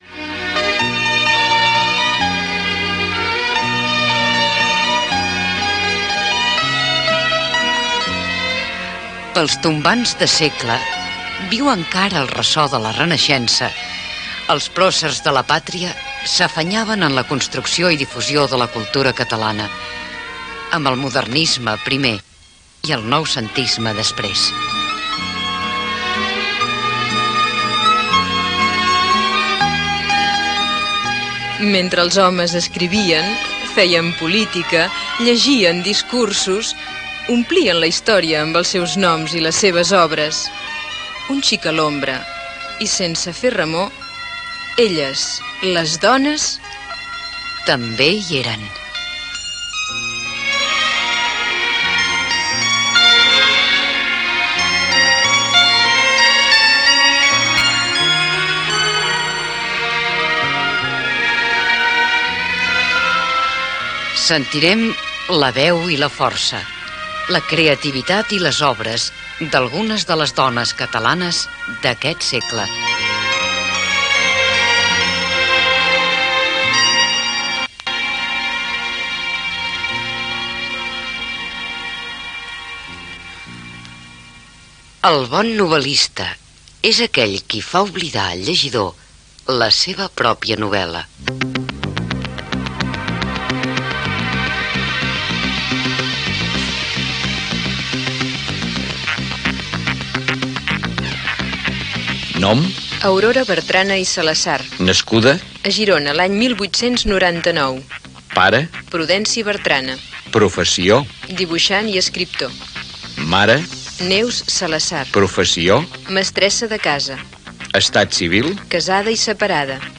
6c6d8050e5b32d9e6e8a98ff078a1ece6a83e0ea.mp3 Títol Ràdio 4 - També hi eren Emissora Ràdio 4 Cadena RNE Titularitat Pública estatal Nom programa També hi eren Descripció Careta del programa, perfil biogràfic d'Aurora Bertrana i espai dedicat a l'escriptora i intèrpret musical. Hi intervé l'escriptora Maria Antònia Oliver